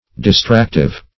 Search Result for " distractive" : The Collaborative International Dictionary of English v.0.48: Distractive \Dis*trac"tive\, a. Causing perplexity; distracting.